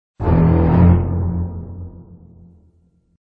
Chaan chan - tension incomodo